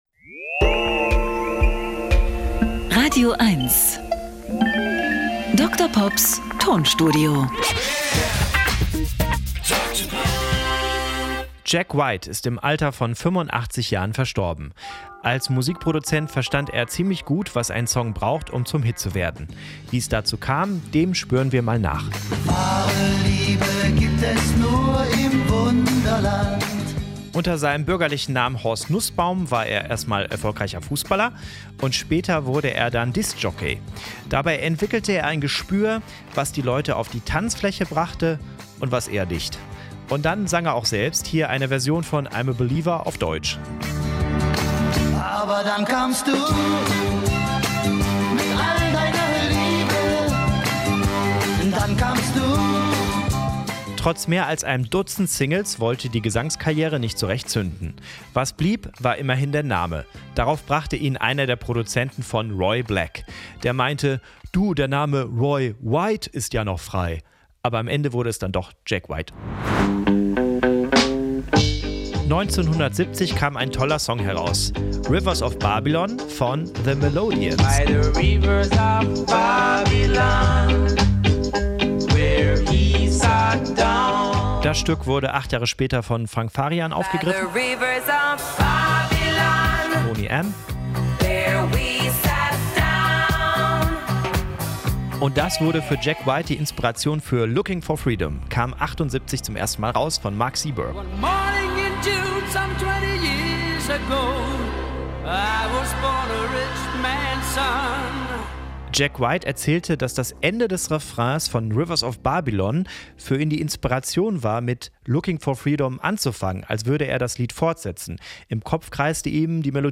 Comedy und Kabarett.
Er therapiert mit Musiksamples und kuriosen, aber völlig wahren Musikfakten.